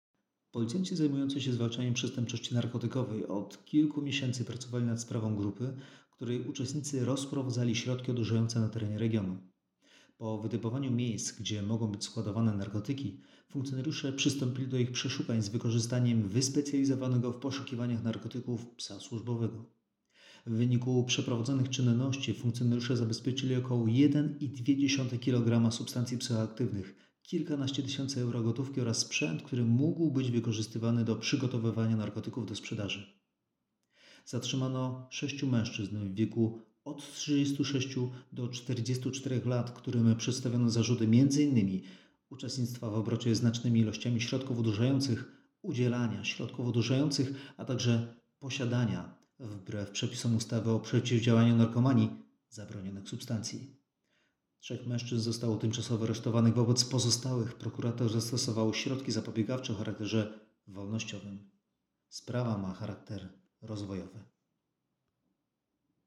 Nagranie audio Skuteczne działania policjantów zwalczających przestępczość narkotykową - mówi komisarz